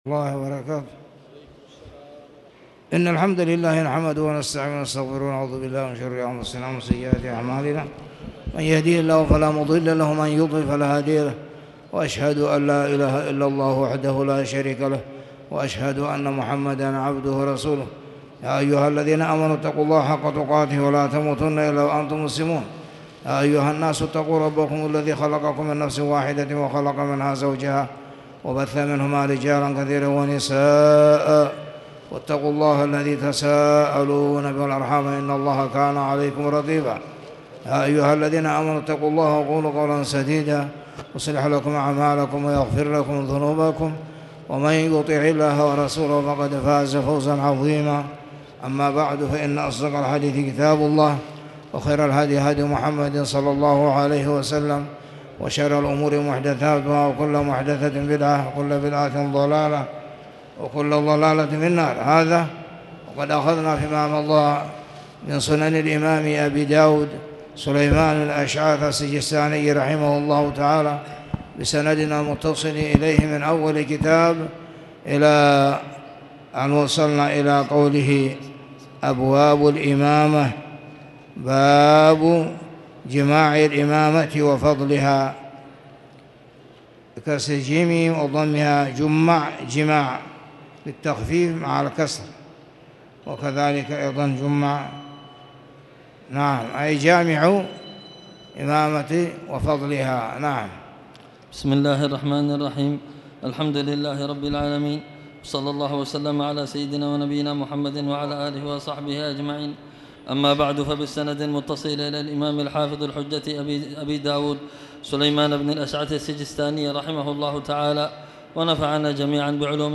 تاريخ النشر ٣ محرم ١٤٣٨ هـ المكان: المسجد الحرام الشيخ